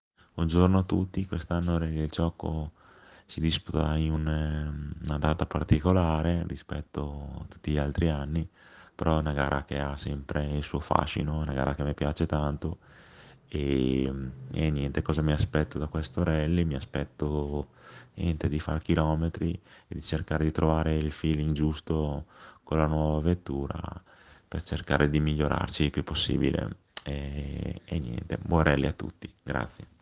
Interviste pre-gara